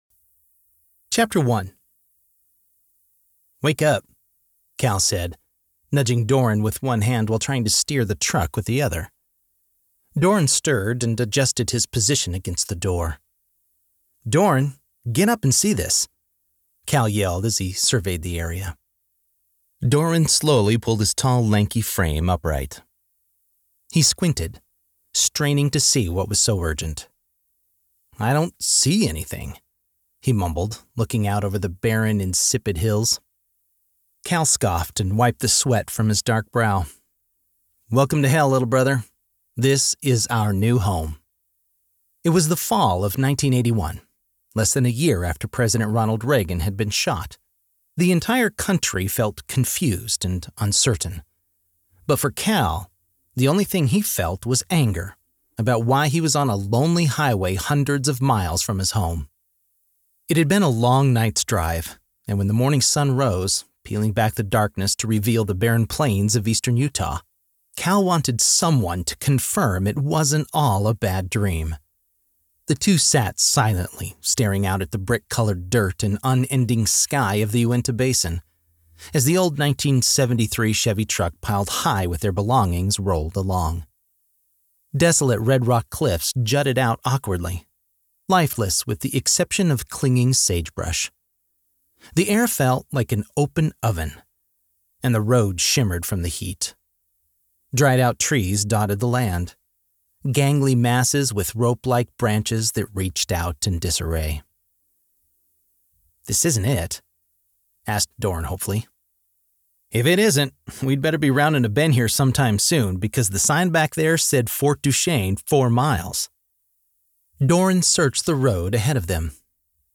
A professional voice actor with a warm, trustworthy, and versatile sound.
Audiobook Sample